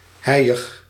Ääntäminen
Synonyymit flou caligineux Ääntäminen France: IPA: [bʁy.mø] Haettu sana löytyi näillä lähdekielillä: ranska Käännös Konteksti Ääninäyte Adjektiivit 1. nevelig kuvaannollinen Muut/tuntemattomat 2. mistig 3. heiig Suku: m .